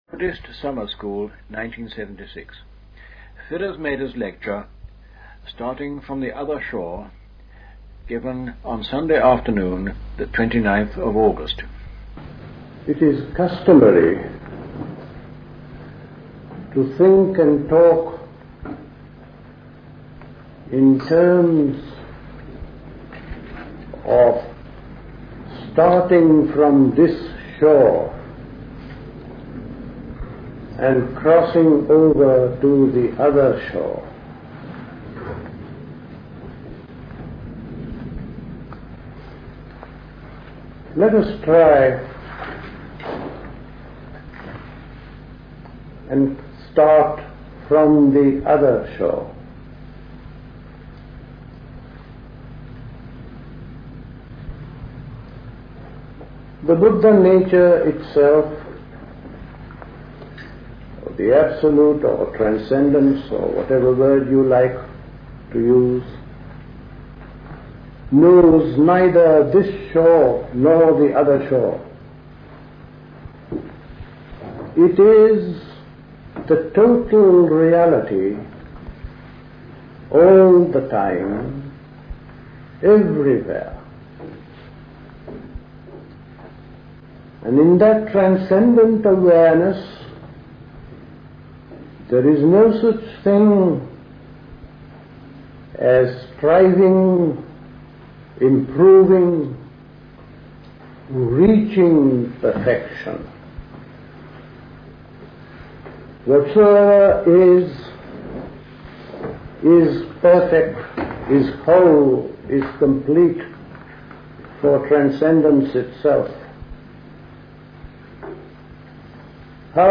A talk
High Leigh Conference Centre, Hoddesdon, Hertfordshire
The Buddhist Society Summer School Talks